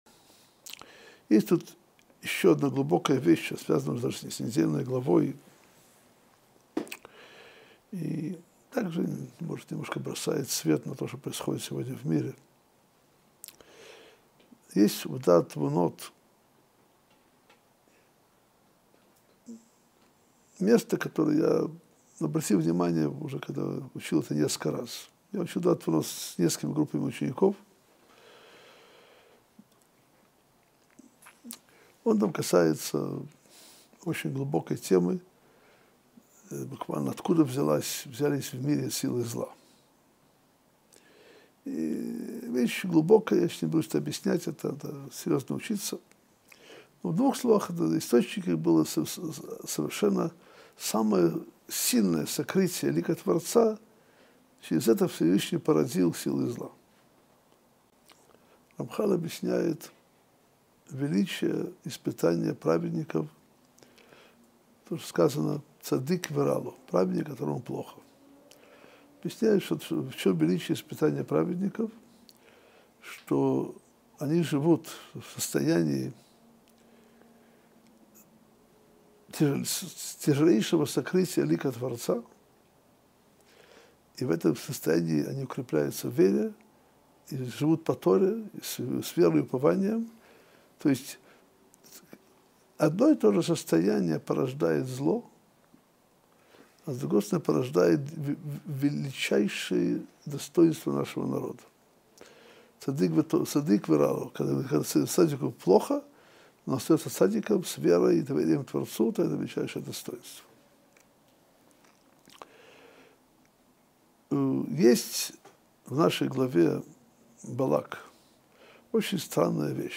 Содержание урока: Откуда появилось зло в этом мире? В чем величие испытания праведников? Почему такой мерзавец как Билам удостоился такого великого пророчества?